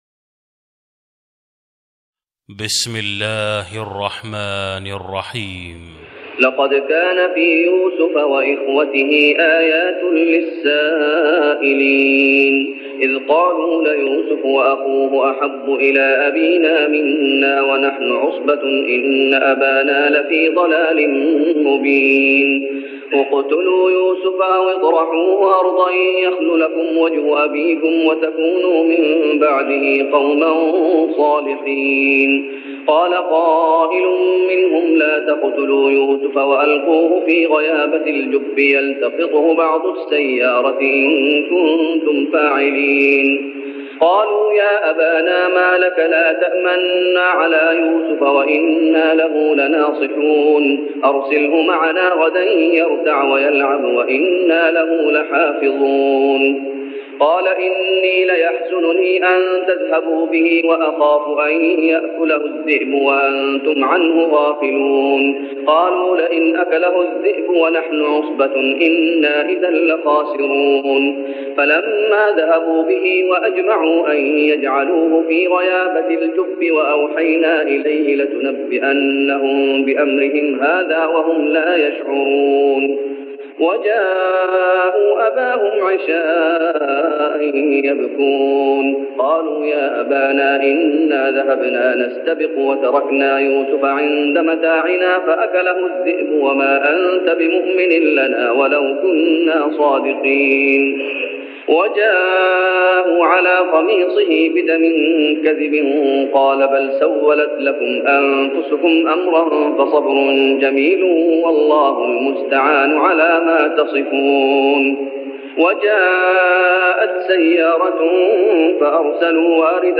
تهجد رمضان 1410هـ من سورة يوسف (7-68) Tahajjud Ramadan 1410H from Surah Yusuf > تراويح الشيخ محمد أيوب بالنبوي عام 1410 🕌 > التراويح - تلاوات الحرمين